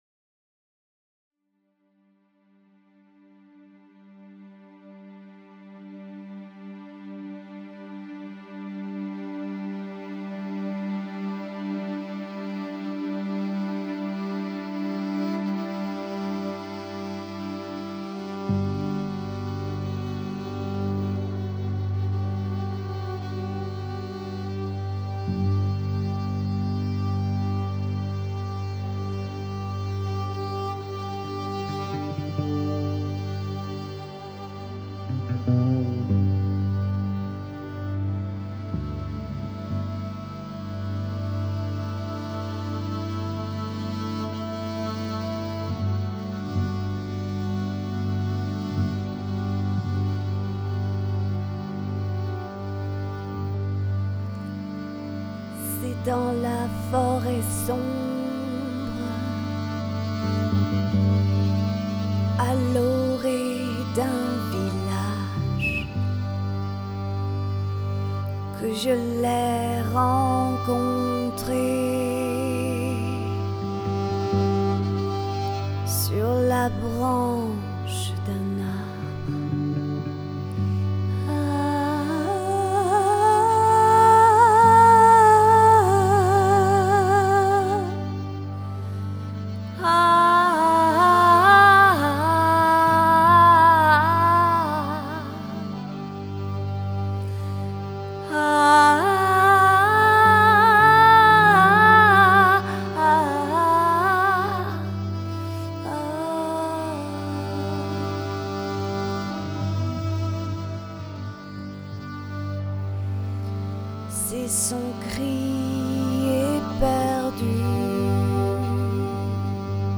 Voix-piano